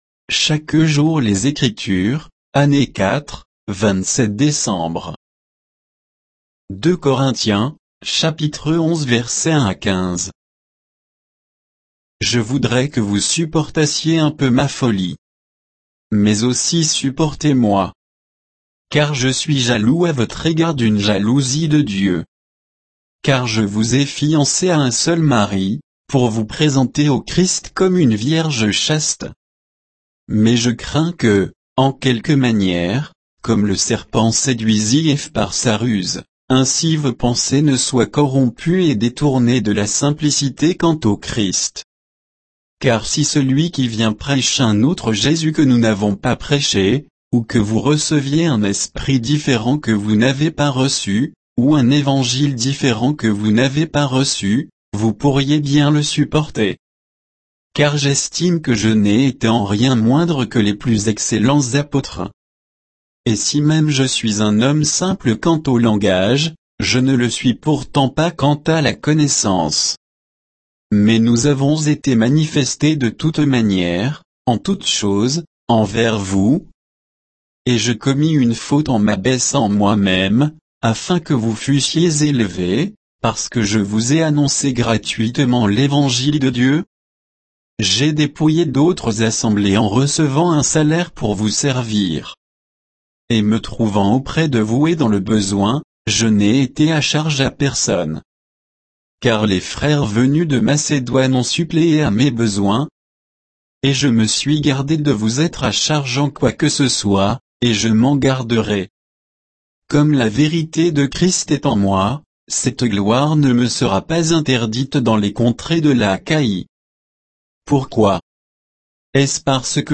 Méditation quoditienne de Chaque jour les Écritures sur 2 Corinthiens 11